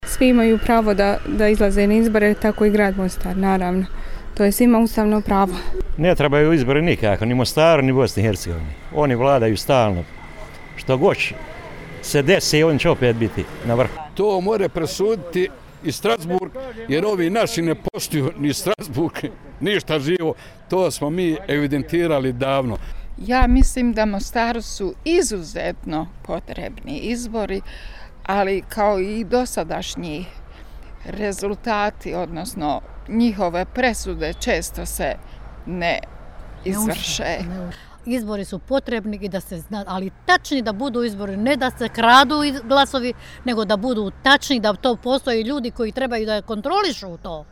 Mostarci u RSE anketi slažu se: